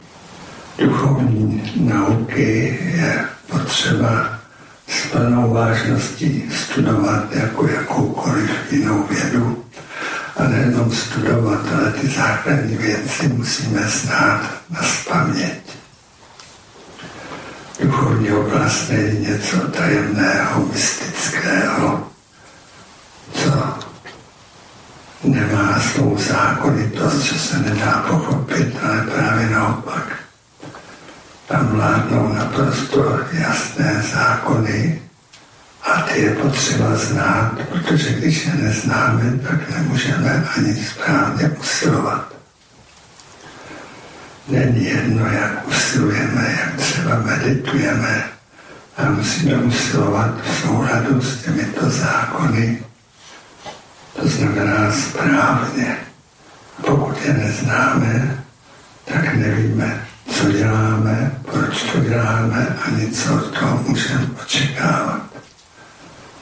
Audiokniha
Nejedná se o studiovou nahrávku.